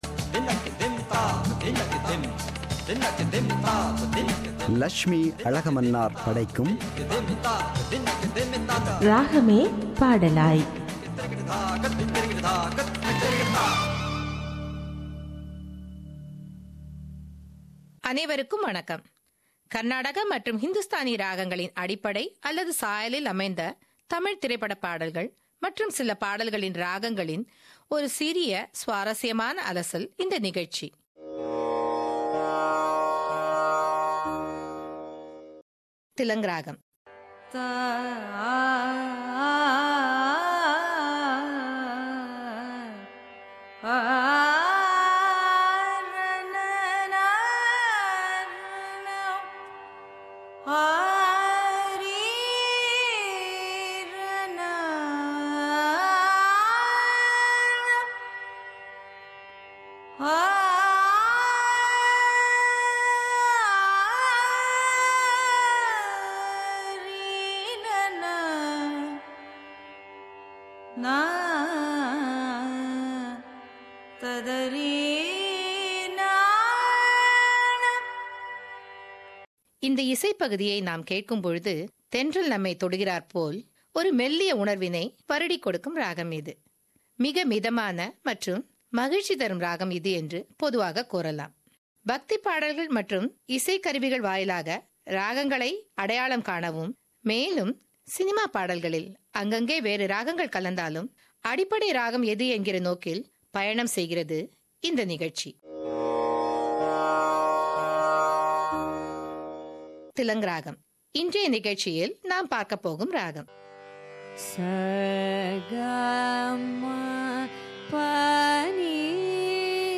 இன்றைய நிகழ்ச்சியில் திலங் ராகம் குறித்து விளக்குகிறார். ராகம் எப்படி பாடல் வடிவம் பெறுகிறது என்பதை கர்நாடக இசை மற்றும் திரைப்பட இசை ஒலிக்கீற்றுகளைக் கலந்து இந்நிகழ்ச்சியை முன்வைக்கிறார்